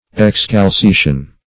Search Result for " excalceation" : The Collaborative International Dictionary of English v.0.48: Excalceation \Ex*cal`ce*a"tion\, n. The act of depriving or divesting of shoes.